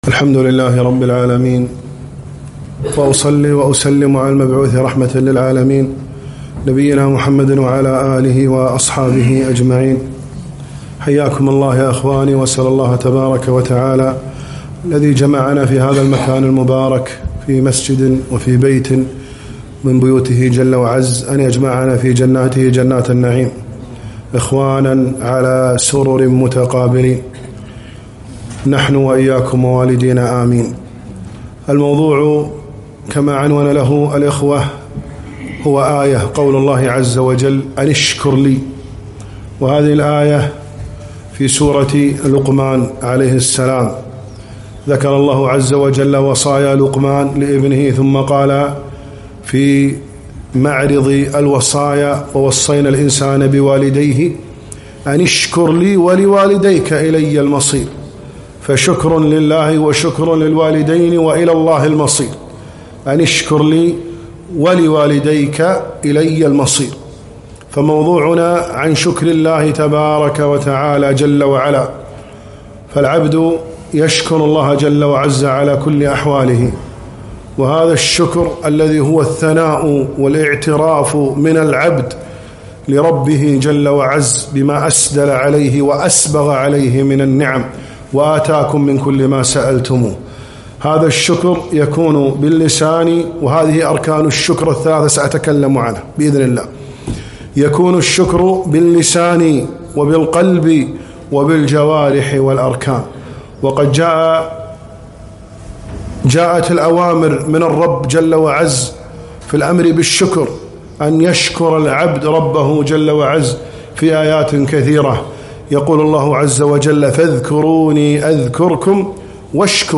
محاضرة - ( أنِ اشكرْ لي )